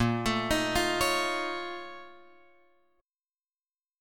A#mM11 chord {6 x 7 8 6 9} chord